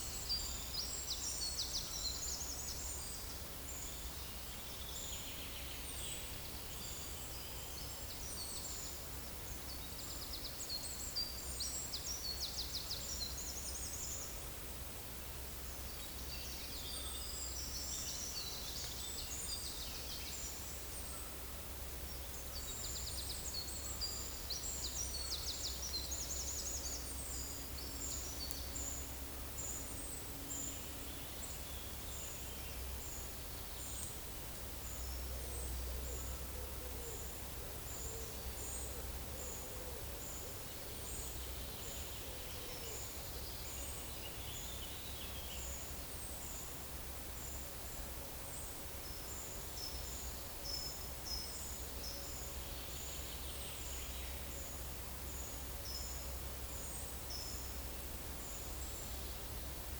Certhia brachydactyla
Certhia familiaris
Turdus iliacus
Columba oenas
Columba palumbus
Troglodytes troglodytes